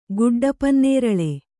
♪ guḍḍa pannēraḷe